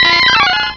-Replaced the Gen. 1 to 3 cries with BW2 rips.
mankey.aif